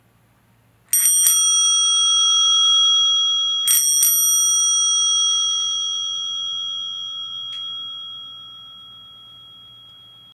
Turtle Bell er smedet i messing, og hver klokke har sin helt unikke klang.
simworks-by-honjo-turtle-bell.m4a